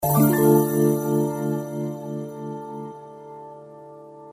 هشدار پیامک